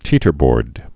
(tētər-bôrd)